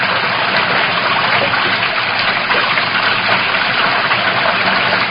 Звук глухой поток воды.